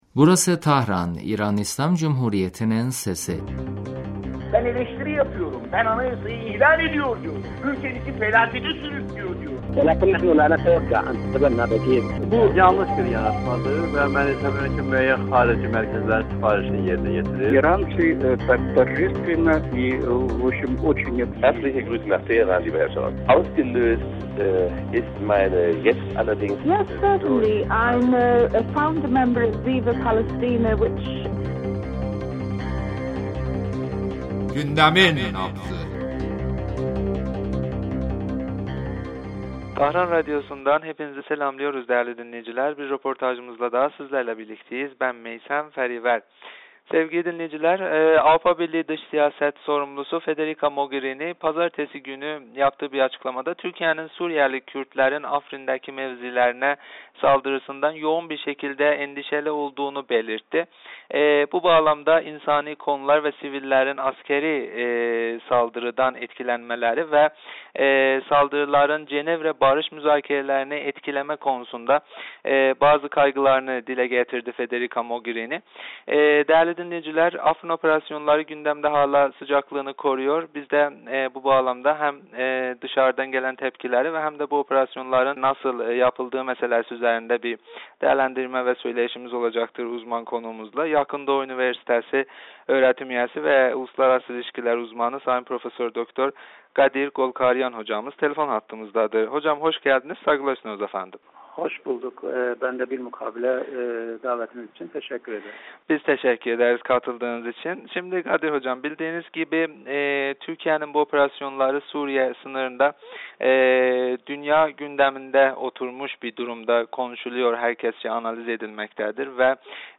radyomuza verdiği demecinde Türkiye'nin Zeytin Dalı operasyonları üzerinde görüşlerini bizimle paylaştı.